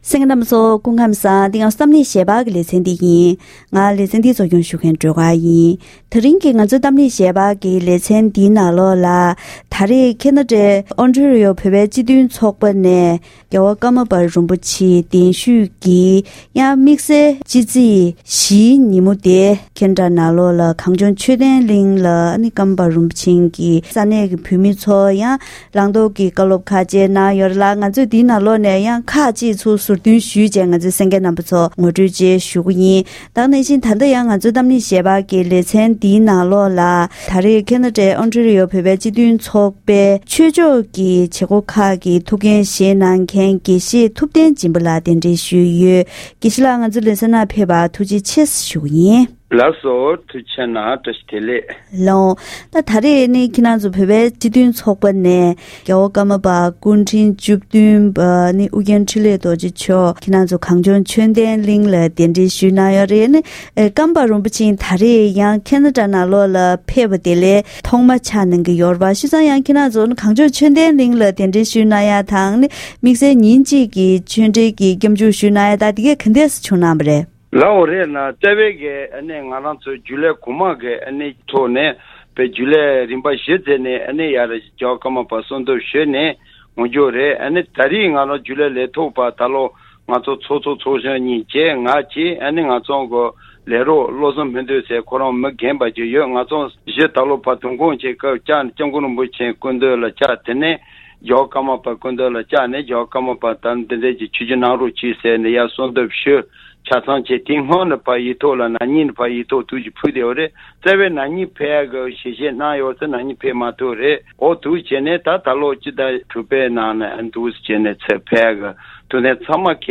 ༄༅། །ད་རིང་གི་གཏམ་གླེང་ཞལ་པར་ལེ་ཚན་ནང་ཁེ་ཎ་ཌའི་གངས་ལྗོངས་ཆོས་ལྡན་གླིང་དུ་རྒྱལ་དབང་ཀརྨ་པ་རིན་པོ་ཆེ་མཆོག་ནས་ས་གནས་ཀྱི་བོད་མི་སྟོང་ཕྲག་བརྒལ་བར་བོད་ཀྱི་སྐད་དང་ཡི་གེ་བོད་མིའི་ངོས་ལ་ཧ་ཅང་གལ་ཆེ་ཆགས་ཀྱི་ཡོད་པས་ཤེས་པ་དགོས་ལ། བོད་པ་ནང་ཁུལ་དང་ཆོས་བརྒྱུད་ནང་ཁུལ་མཐུན་ལམ་བྱ་རྒྱུ་ཧ་ཅང་གལ་ཆེ་ཡིན་པ། དེ་བཞིན་ཀརྨ་པ་རིན་པོ་ཆེ་བོད་ནས་བཙན་བྱོལ་དུ་ཕེབས་དོན་སོགས་འབྲེལ་ཡོད་ངོ་སྤྲོད་གནང་ཡོད་པའི་ཞིབ་ཕྲའི་གནས་ཚུལ་ཞིག་གསན་རོགས་གནང་།